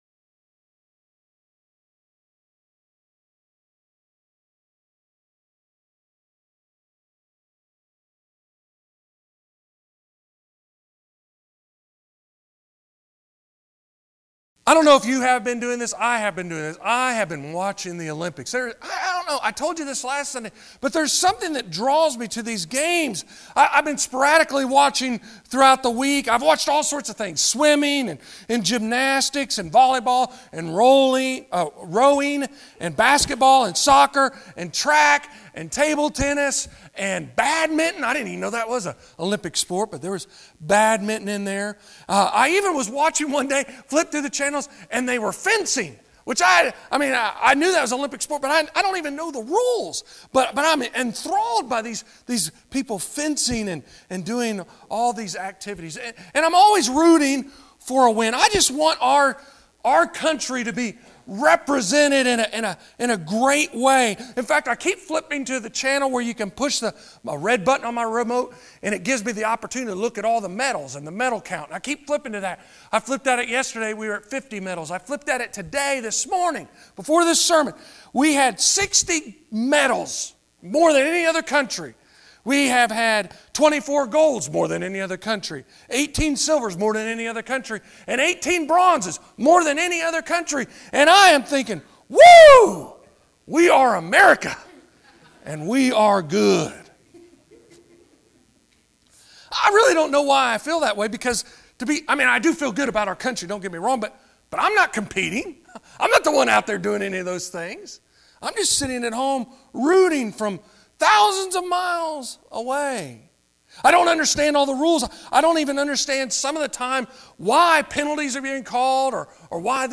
31:36 Sermons in this series The Cross Is Our Victory!